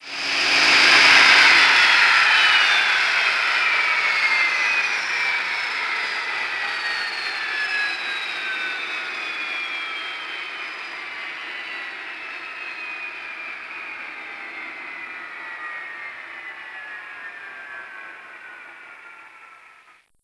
shutdown.wav